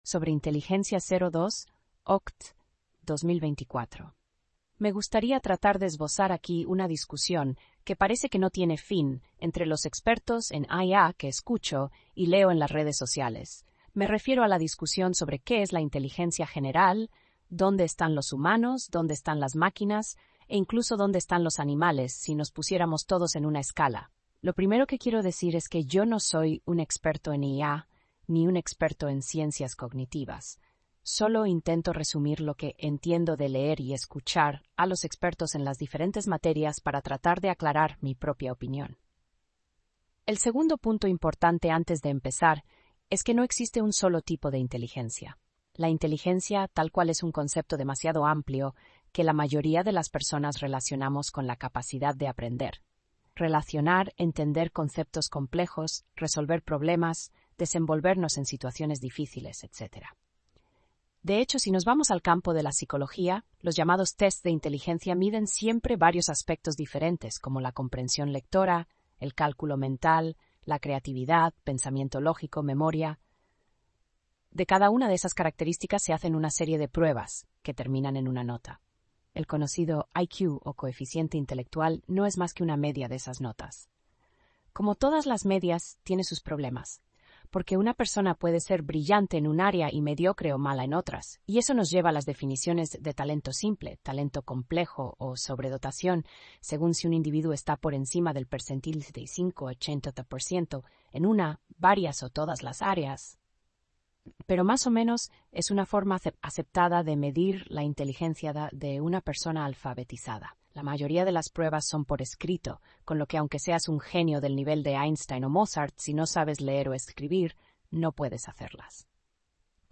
(audio generado con la API de tts de OpenAI)